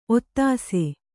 ♪ ottāse